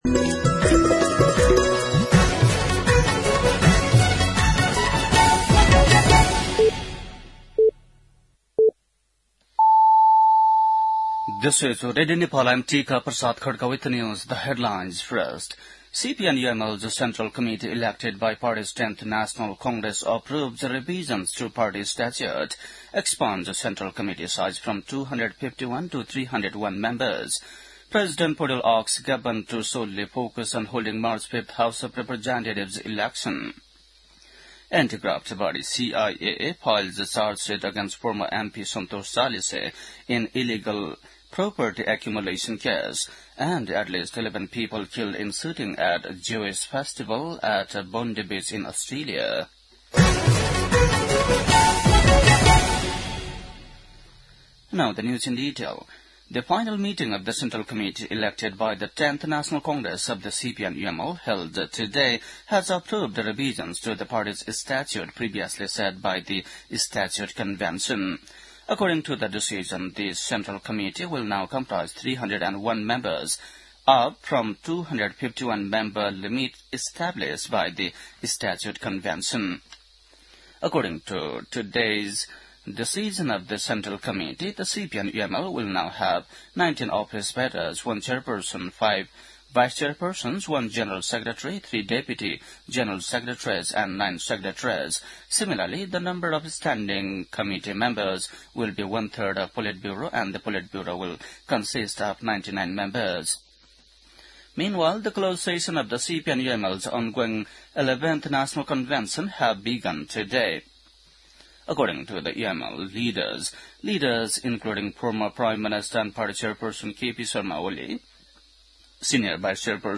बेलुकी ८ बजेको अङ्ग्रेजी समाचार : २८ मंसिर , २०८२
8-pm-english-news-.mp3